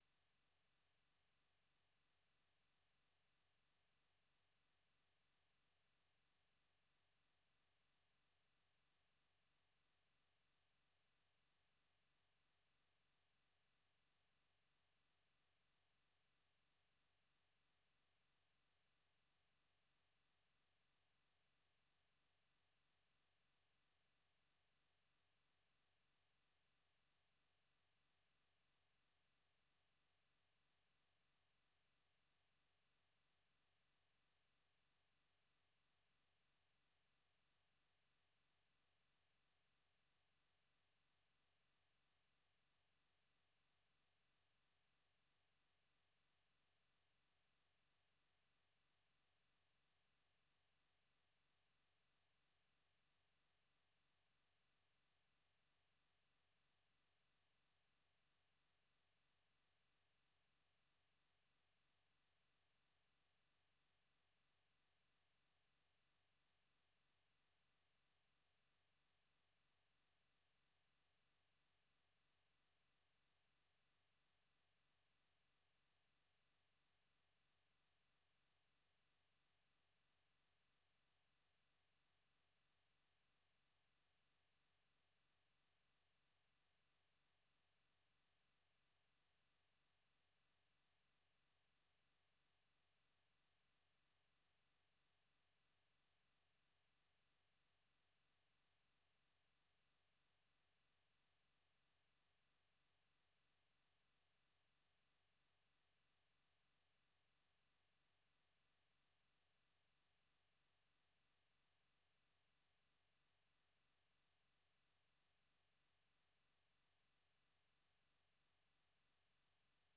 1 Africa News Tonight - January 01, 2026 24:52 Play Pause 8h ago 24:52 Play Pause Play later Play later Lists Like Liked 24:52 Africa News Tonight is a lively news magazine show featuring VOA correspondent reports, interviews with African officials, opposition leaders, NGOs and human rights activists.